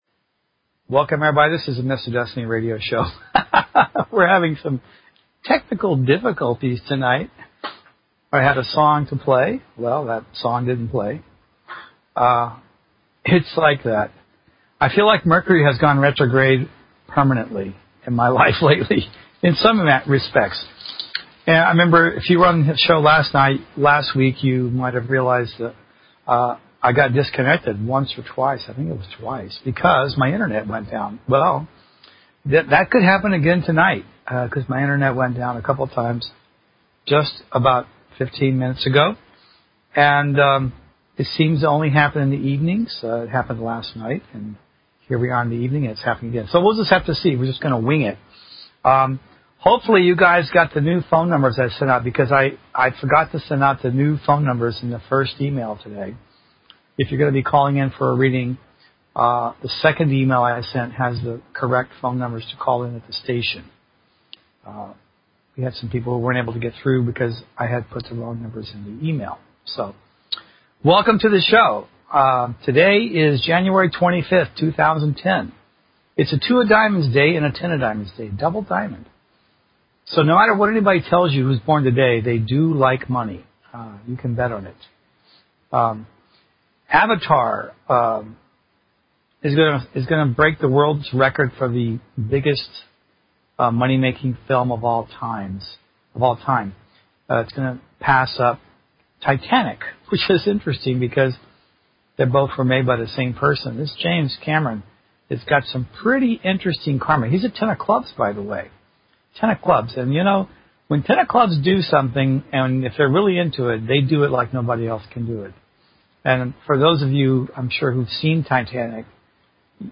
Talk Show Episode
Predictions and analysis. Guest interview or topic discussion.